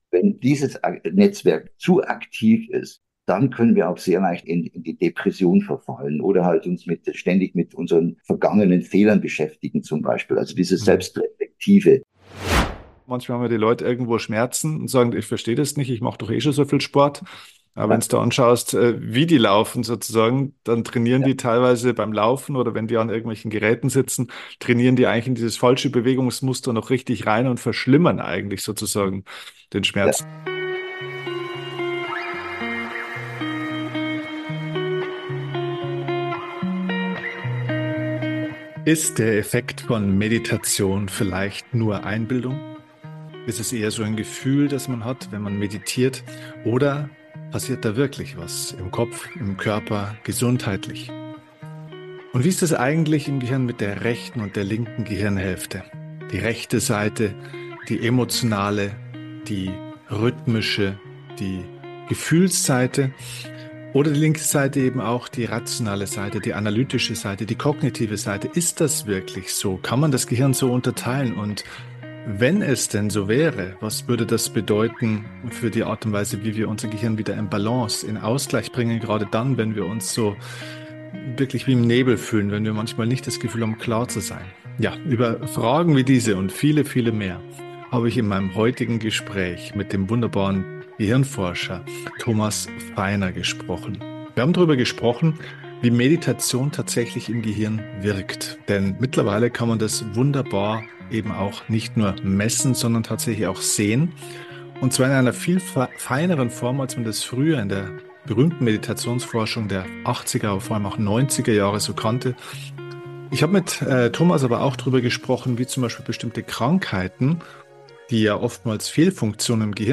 #561 - Was macht Meditation mit dem Gehirn? Interview